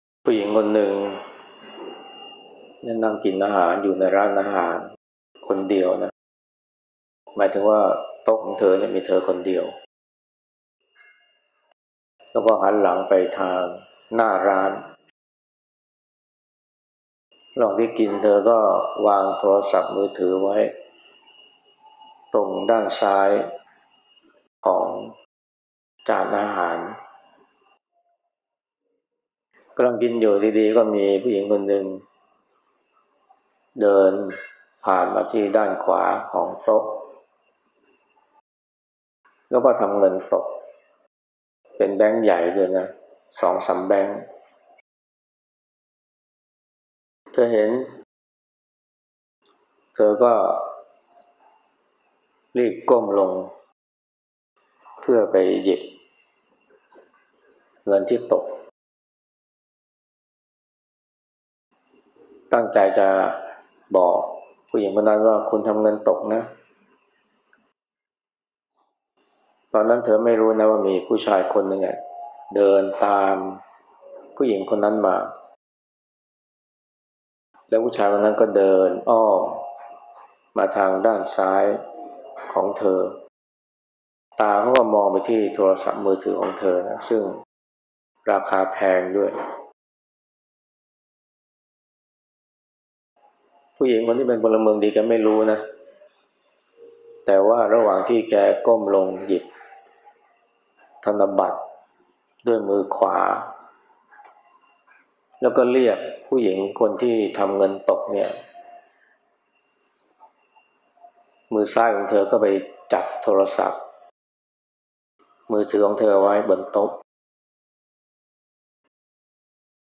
ฟังธรรมะ Podcasts กับ พระอาจารย์ไพศาล วิสาโล